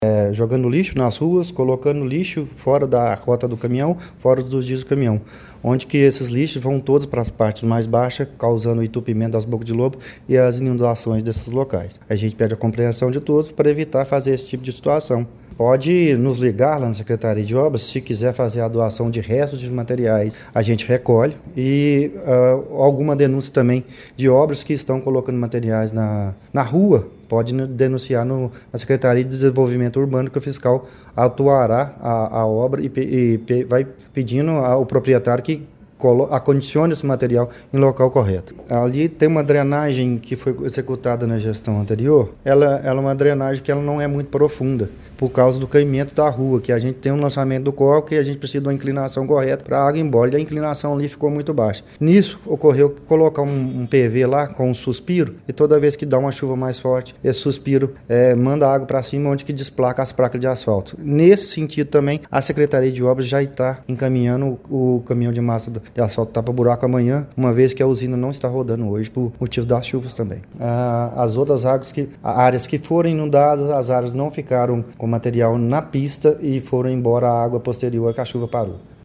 Outro ponto enfatizado na coletiva foi a responsabilidade compartilhada da população na prevenção de alagamentos.